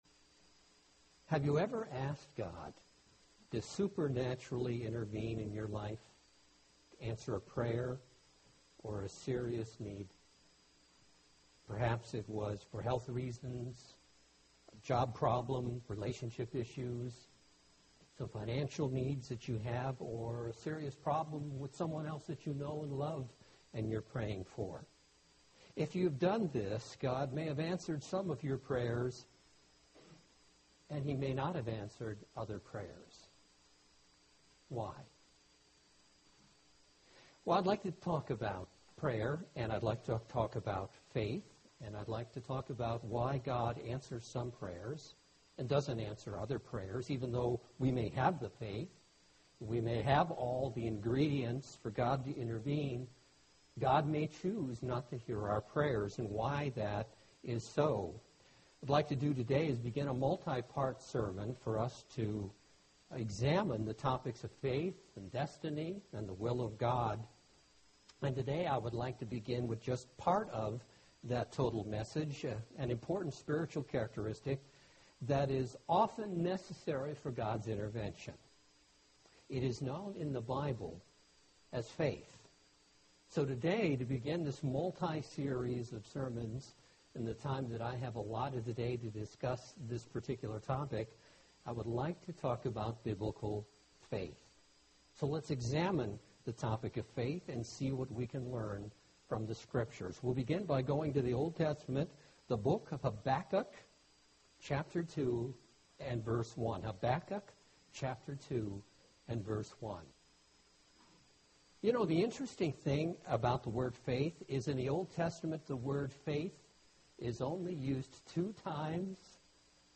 This is the first part of a multi-part sermon. In this Sermon we will focus on Biblical faith, which is necessary for God's intervention.